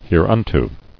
[here·un·to]